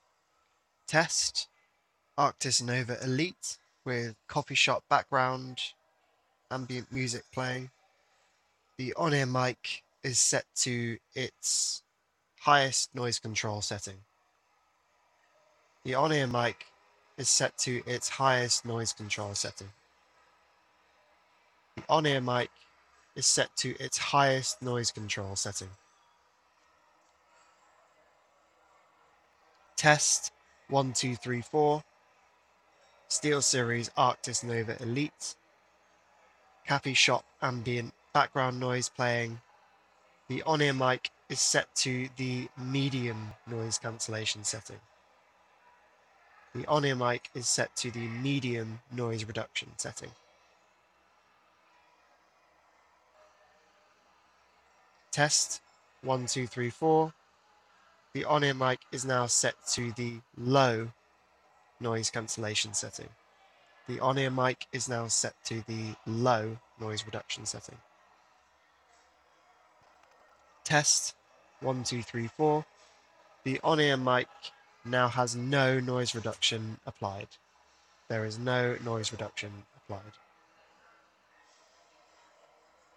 In the first clip below, I played a coffee shop ambient noise backing track at around 50% volume on my iPhone 17 Pro Max, which is still audible in the recording. There isn’t a huge difference between the ‘High’ and ‘Low’ settings either.
Arctis Nova Elite Mic Test Mic NR 1.mp3